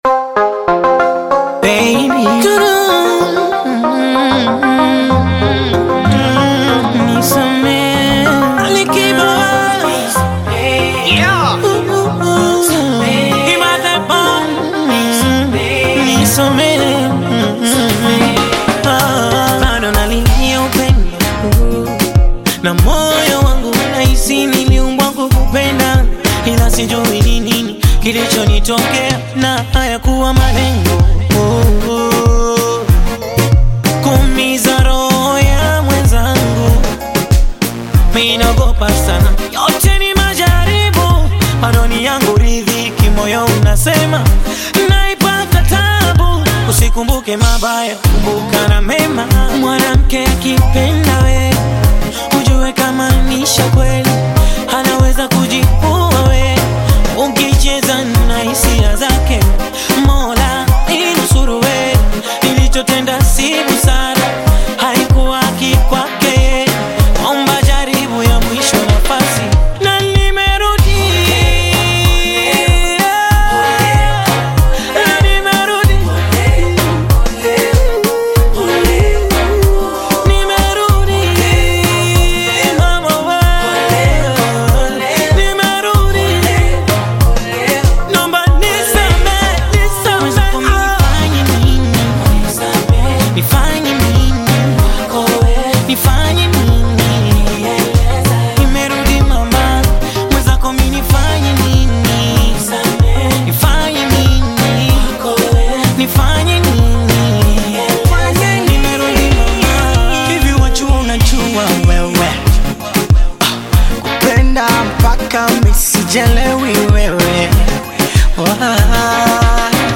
Bongo fleva